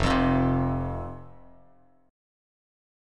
F5 Chord
Listen to F5 strummed